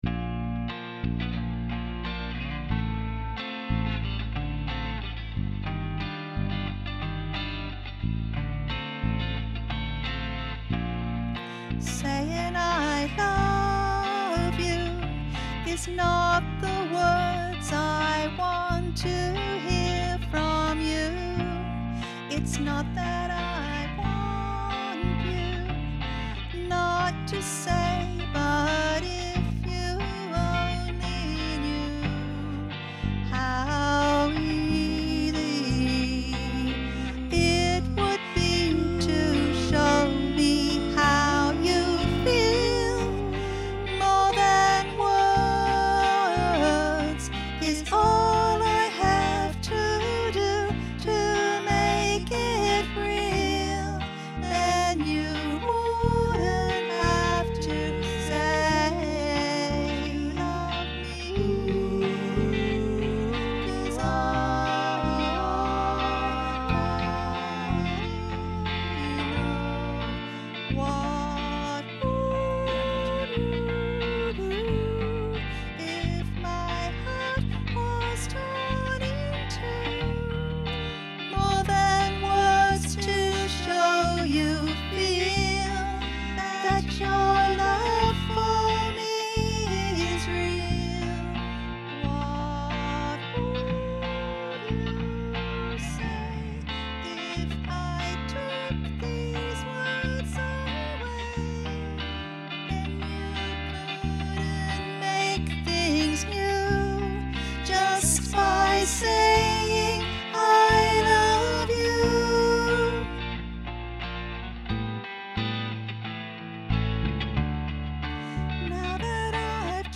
Vox Populi Choir is a community choir based in Carlton and open to all comers.
MoreThanWords_Alto.mp3